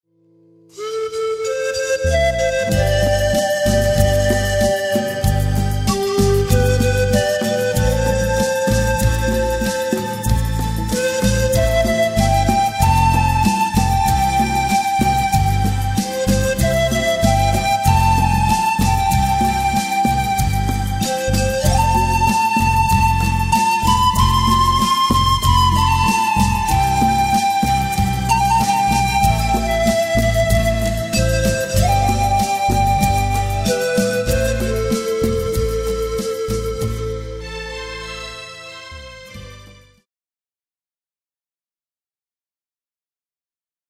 Relaxing sounds of the powerful pan flute.